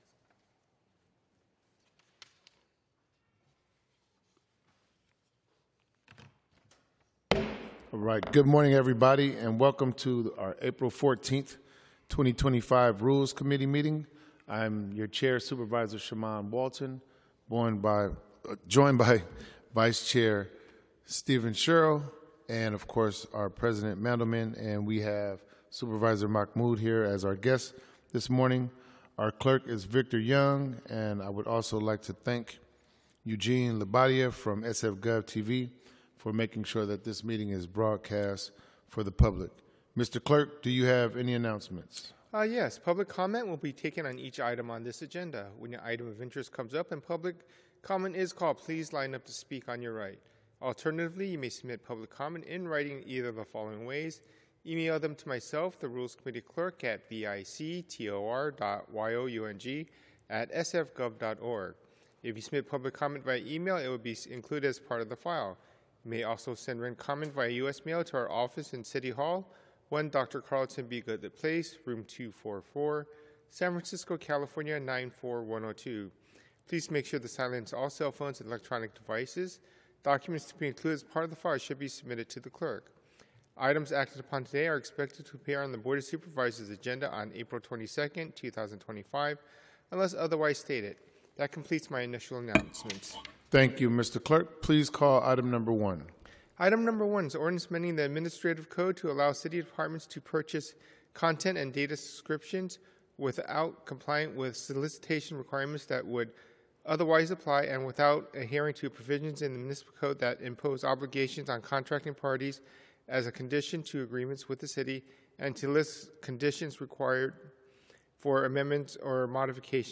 Rules Committee - Regular Meeting - Apr 14, 2025